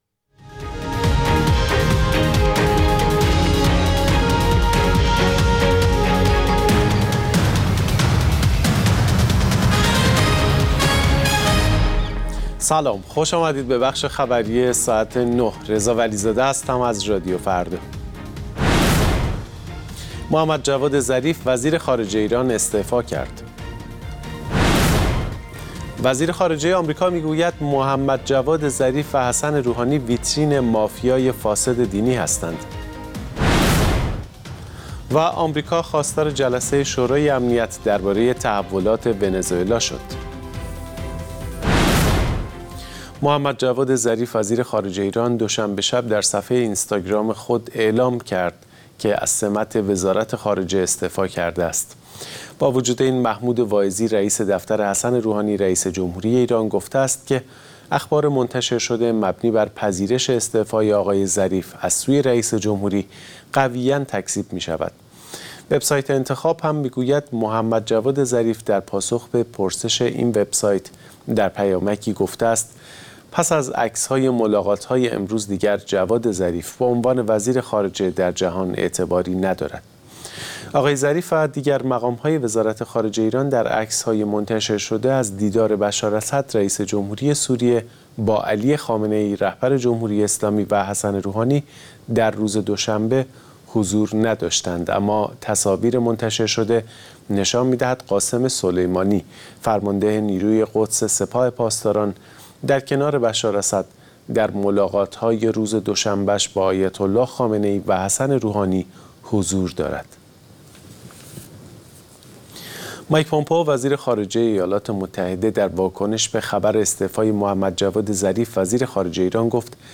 اخبار رادیو فردا، ساعت ۹:۰۰